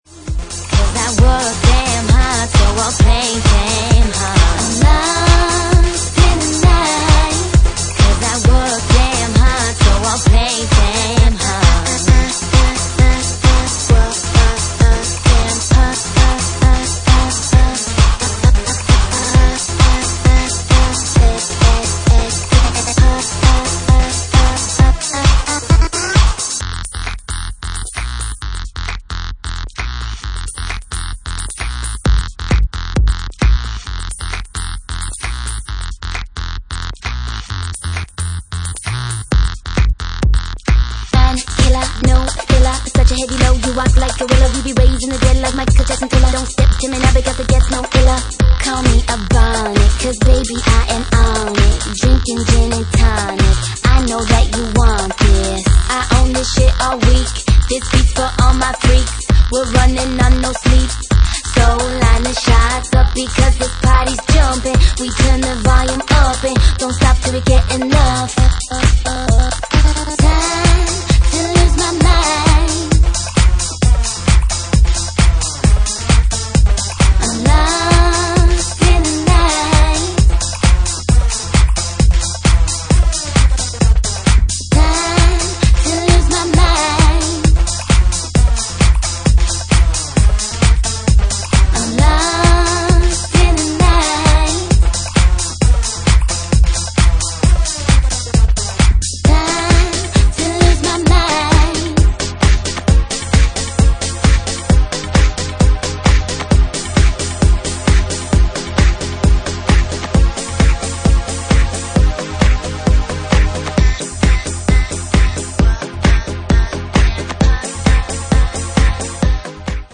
Genre:Jacking House
Jacking House at 133 bpm